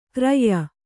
♪ krayya